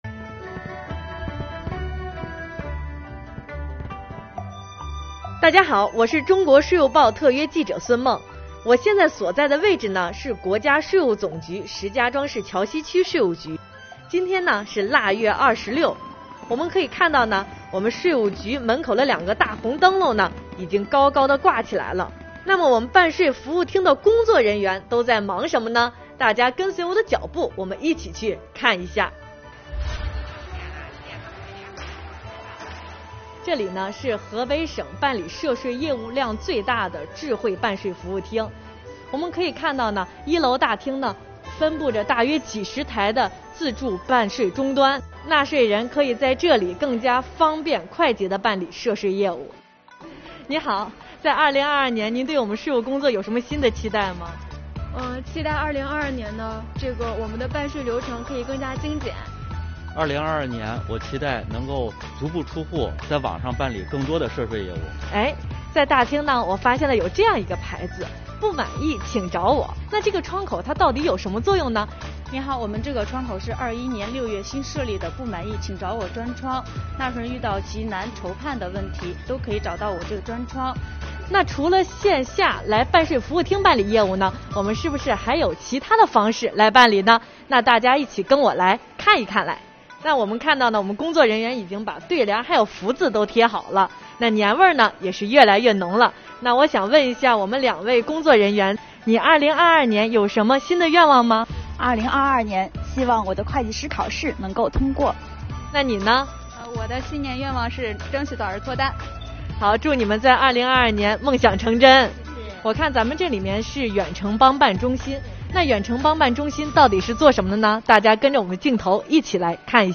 虎年春节将至，本报记者带您走进国家税务总局石家庄市桥西区税务局办税服务厅，听一听纳税人缴费人和税务人在新的一年有哪些新期盼。